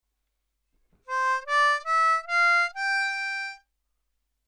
Now let’s learn the chunks.